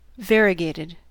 Ääntäminen
Ääntäminen US Tuntematon aksentti: IPA : /ˈvæɹiəˌɡeɪtɪd/ IPA : /ˈveərɪəˌɡeɪtɪd/ Haettu sana löytyi näillä lähdekielillä: englanti Variegated on sanan variegate partisiipin perfekti.